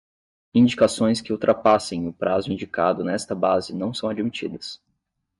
Pronounced as (IPA) /ˈpɾa.zu/